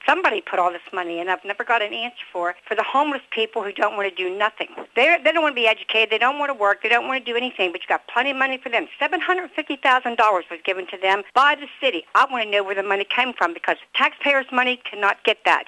A caller to WCBC’s morning show was concerned about possible cuts to the Allegany County Library System. She wondered why the Edwards Fund was not approached about the situation or the City of Cumberland.